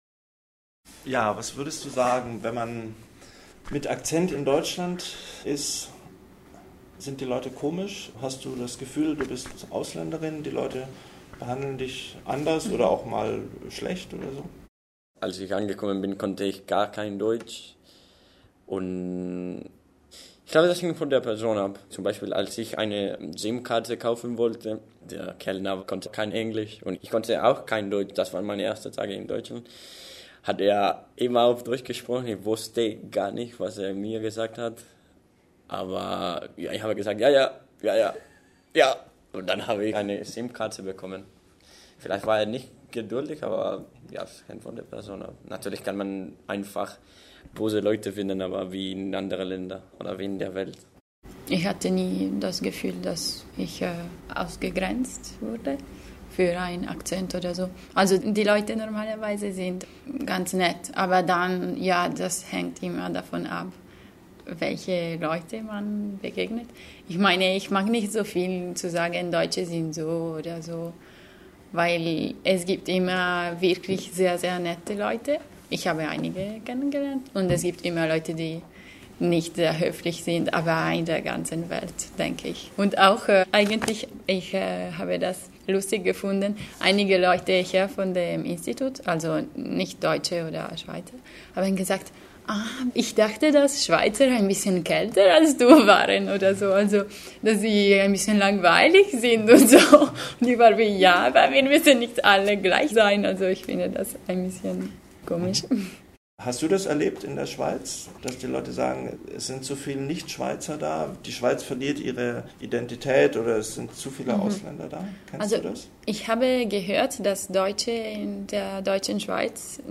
Sechs Jugendliche aus sechs verschiedenen Ländern diskutieren über Rassismus, Toleranz und Vorurteile... in Deutschland, in Freiburg, aber auch in ihren Heimatländern.